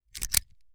Part_Assembly_41.wav